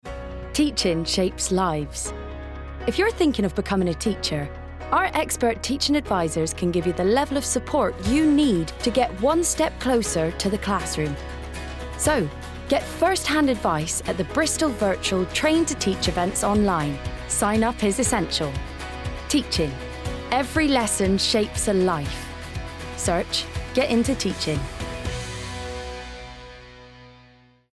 Modern/Fresh/Engaging
Get Into Teaching (West Country accent)